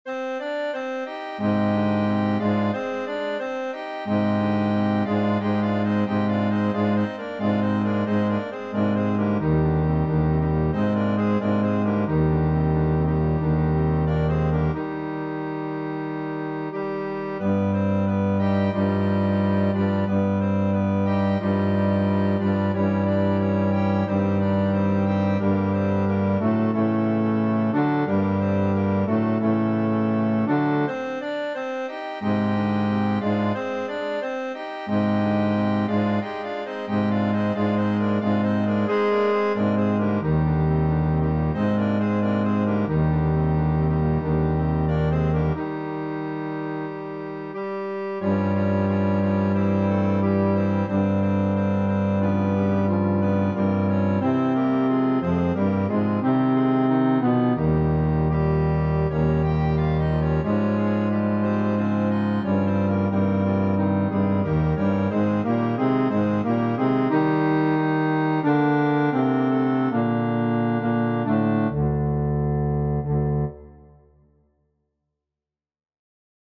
FF:HV_15b Collegium male choir
Na_prievoze-Bas.mp3